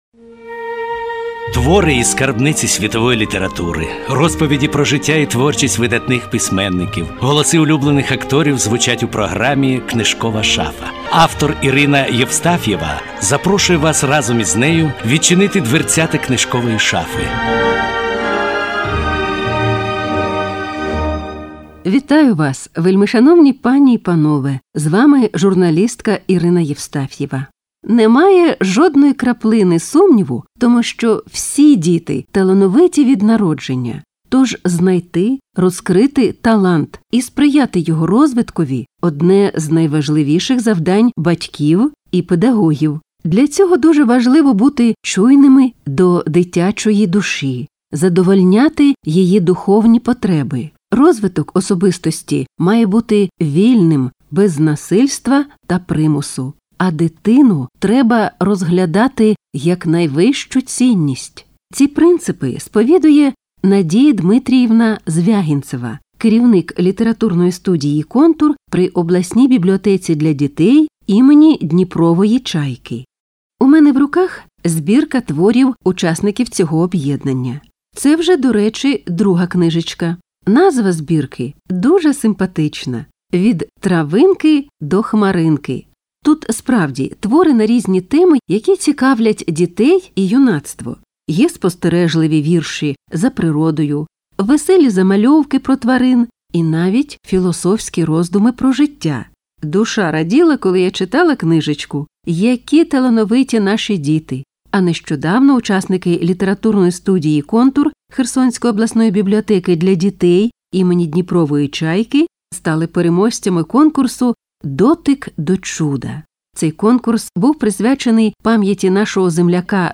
"Контурівці" у радіоефірі